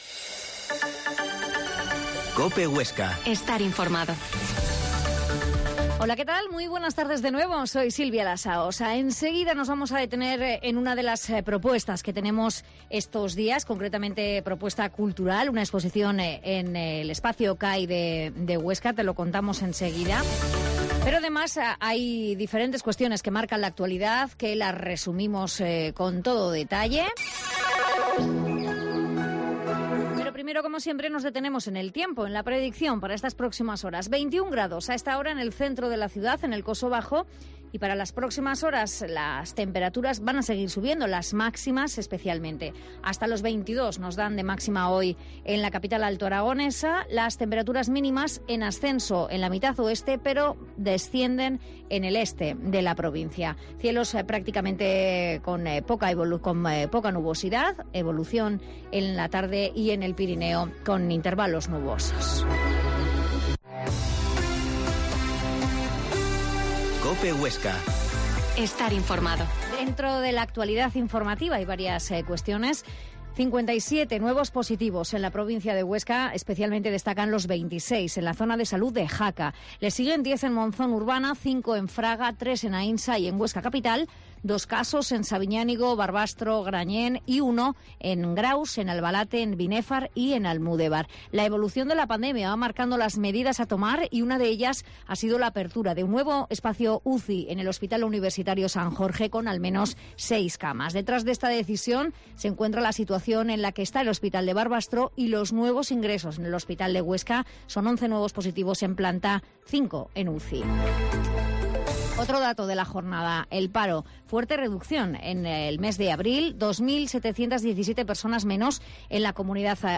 La Mañana en COPE Huesca - Informativo local Mediodía en Cope Huesca 13,20h.